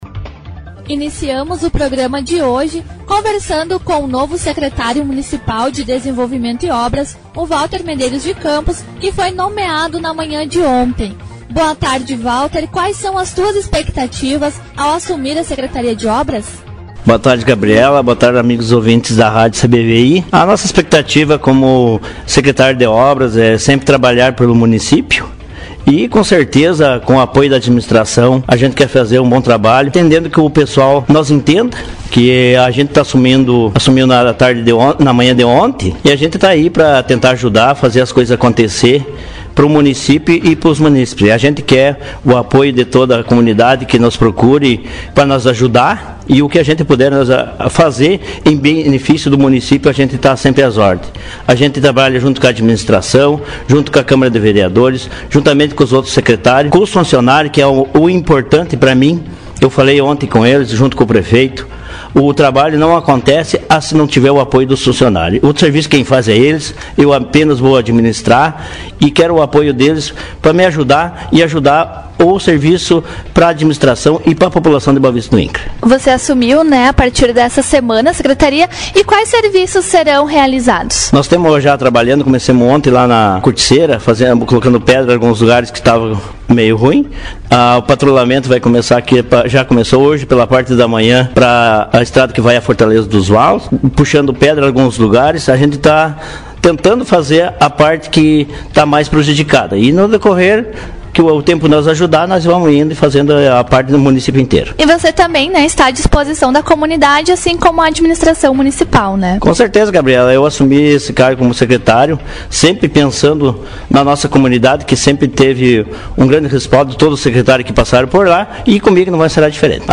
Entrevista com o Secretário de Desenvolvimento e ... mp3 Publicado em 17/09/18 Formato: audio/mpeg